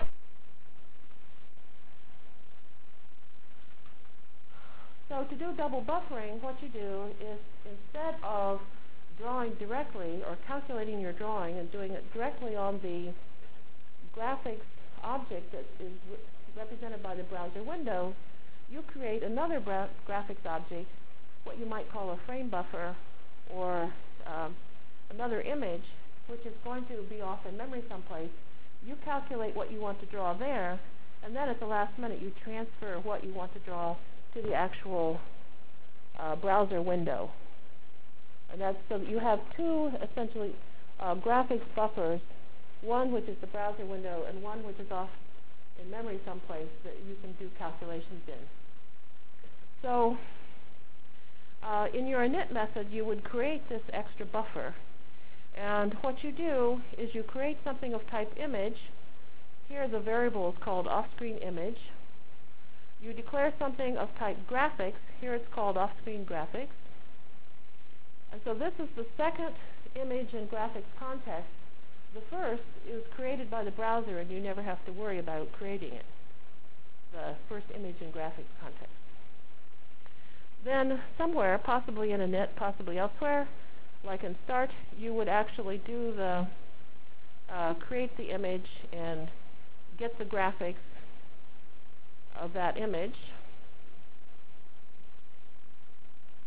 From Jan 29 Delivered Lecture for Course CPS616 -- Java Lecture 3 -- Exceptions Through Events CPS616 spring 1997 -- Jan 29 1997.